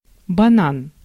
Ääntäminen
IPA: [ɛn.baˈnɑːn]